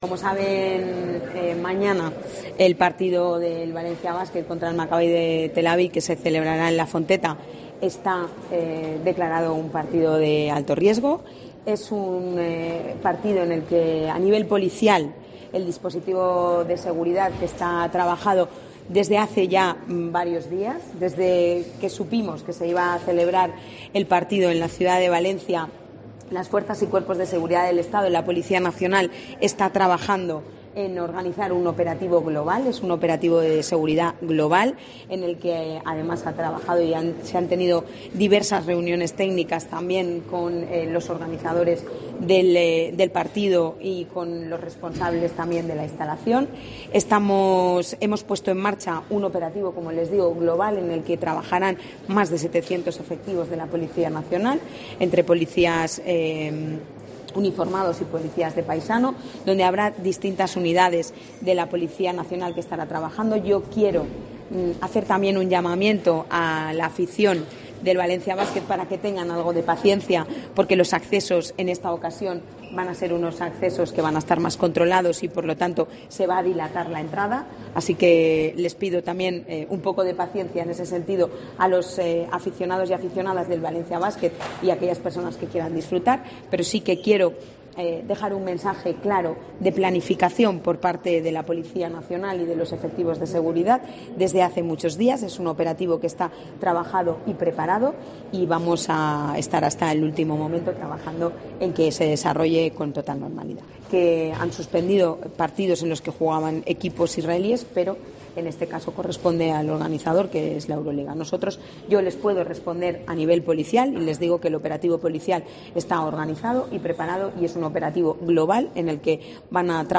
Pilar Bernabé, delegada del gobierno en la CV, detalla parte del dispositivo antiterrorista